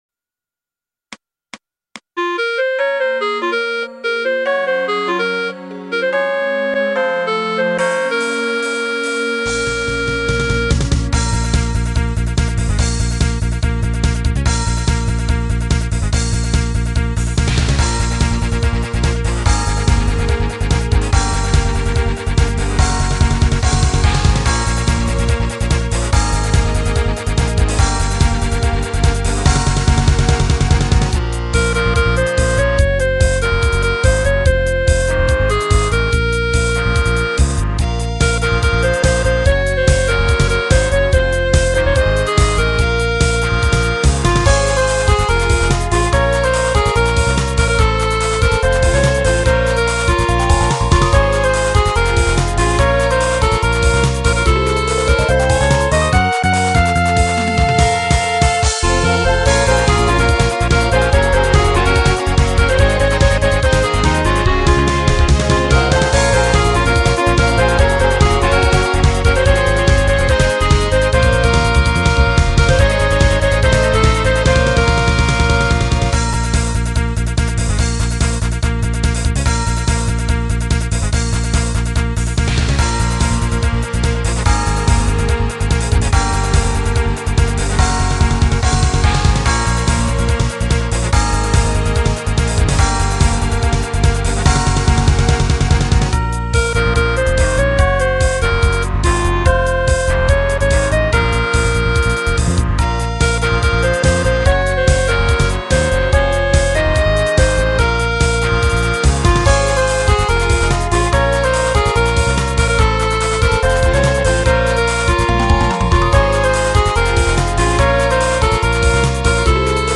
歌詞   メロディアスロック
ギター、ベース、ドラム
ギターがメインで鳴ってる、割と王道（らしい）メロディのロックです。
音源はPC搭載のしょぼいやつなのが残念ですが(´Д⊂
歌入れしやすいようにキーは低めにしてあります。